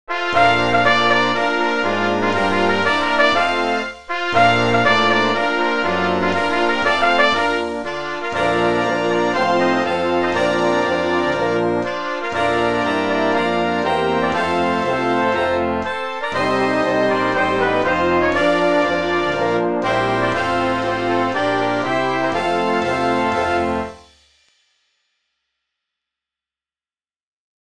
Marche-parade pour harmonie-fanfare,
avec tambours et clairons ad lib.